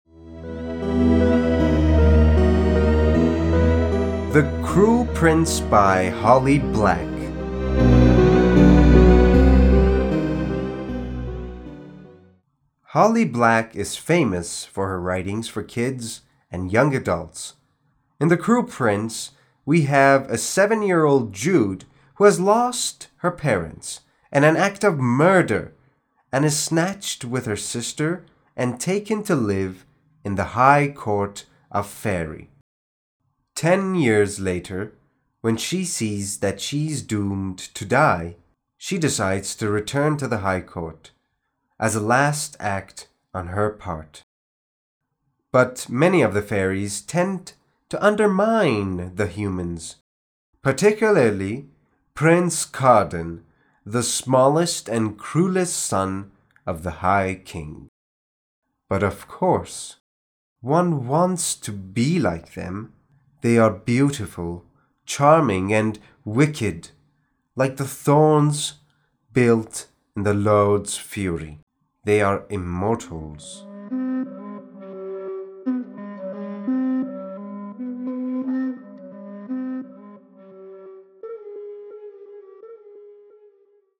معرفی صوتی کتاب The Cruel Prince